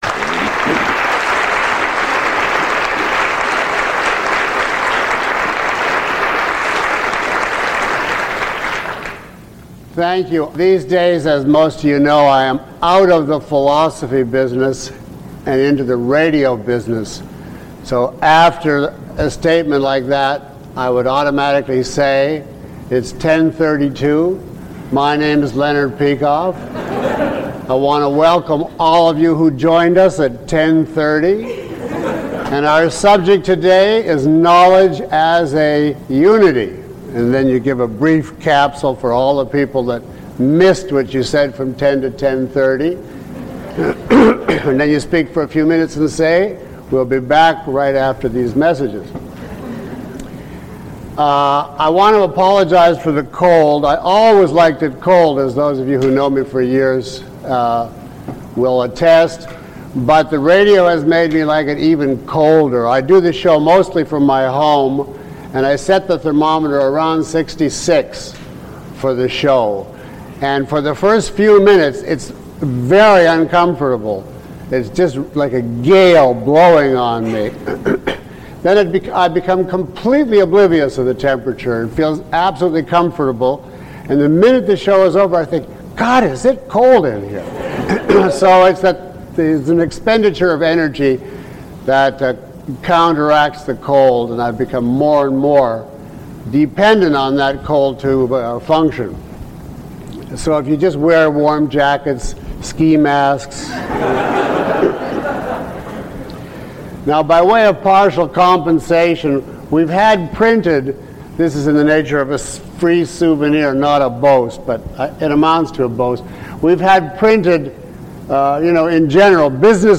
Lecture 01 - Unity in Epistemology and Ethics.mp3